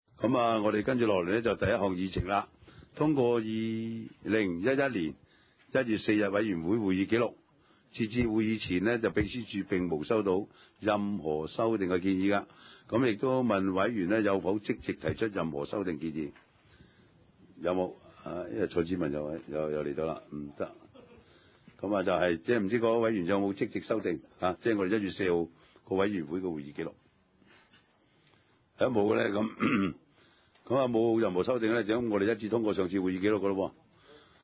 地區設施管理委員會第二十次會議
荃灣民政事務處會議廳